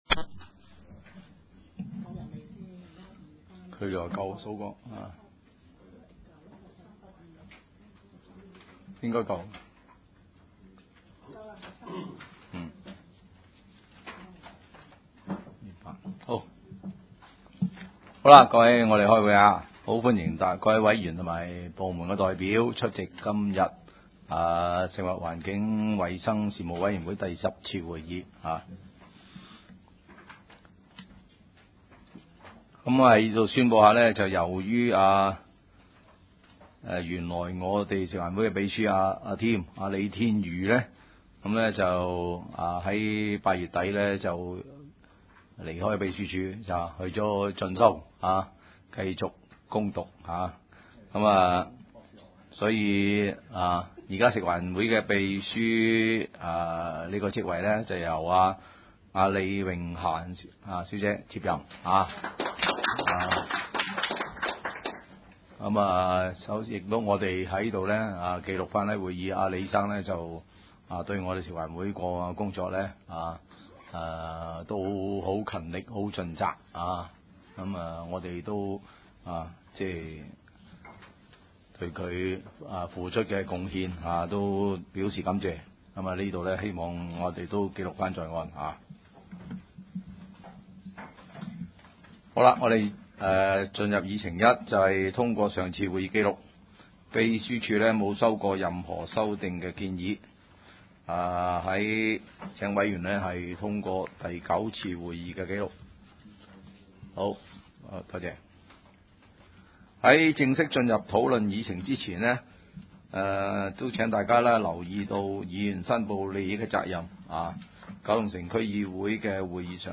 九龍城區議會轄下 食物環境 生事務委員會 第十次會議 日期： 2009 年 10 月 8 日 ( 星期四 ) 時間： 下午 2 時 30 分 地點： 九龍紅磡德豐街 18-22 號 海濱廣場一座 17 樓 九龍城民政事務處會議室 議 程 （會議錄音） 第一部分 ?